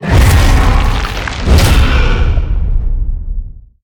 Sfx_creature_shadowleviathan_seatruckattack_enter_01.ogg